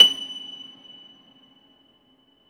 53a-pno24-F5.aif